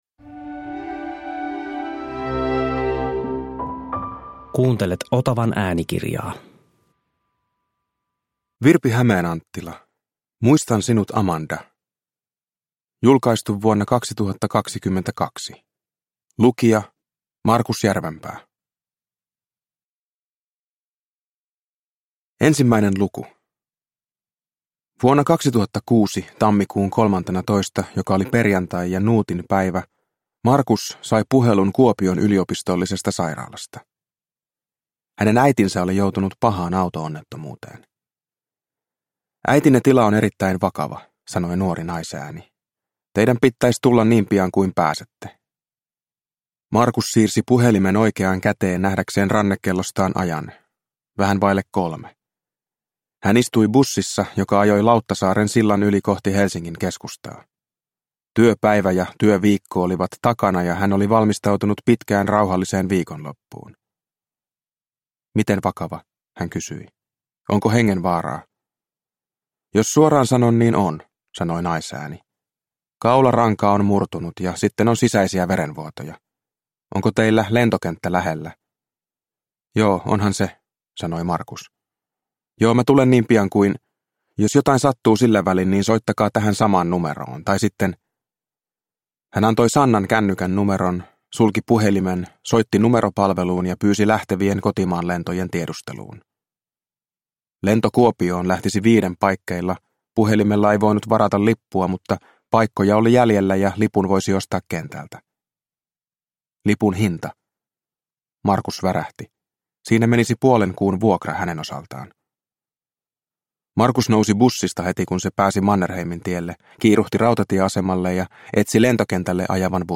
Muistan sinut Amanda – Ljudbok – Laddas ner